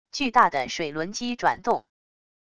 巨大的水轮机转动wav音频